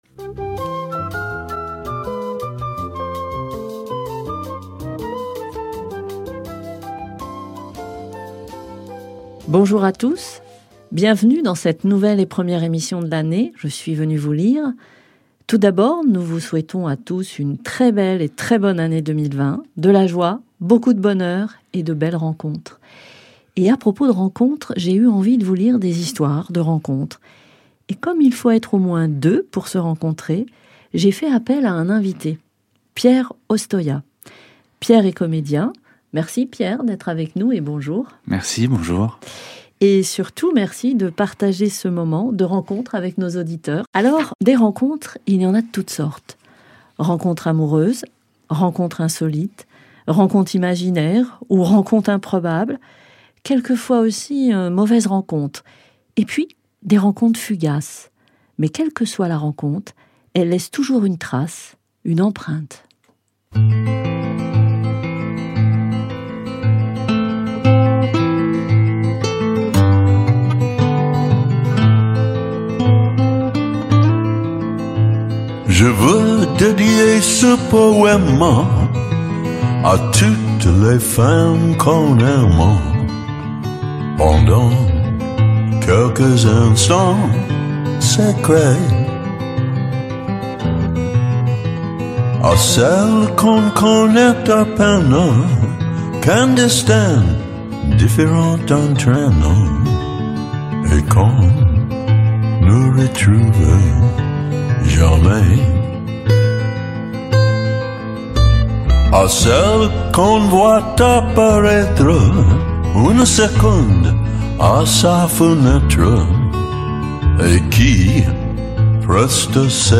Qu'elles soient amoureuses,fortuites où volontaires, insolites où même fugaces toutes nos rencontres laissent des traces plus ou moins profondes Alors, installons nous et laissons nous emporter par la magie des textes et de la musique en nous souvenant, peut être avec nostalgie et mélancolie, de certaines de nos rencontres